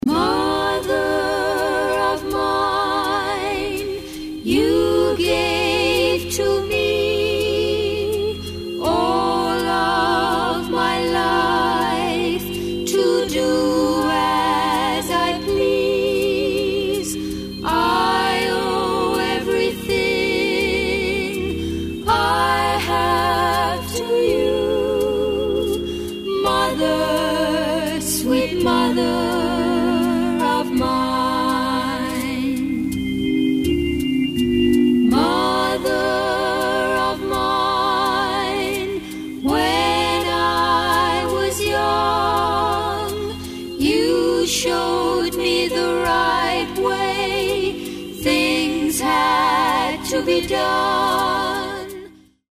digitally remastered